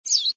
SZ_TC_bird2.ogg